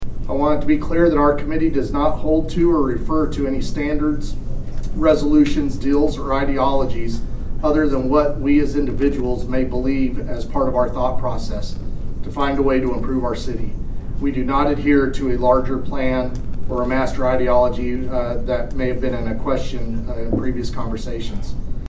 With a packed room and a meeting that lasted more than 3 1/2 hours Monday night, the Bartlesville City Council unanimously reconsidered accepting two grants — one for a citizenship program at the library and another for replacing trees that were destroyed by the May 6, 2024 tornado — but not without a procedural change and some disagreement.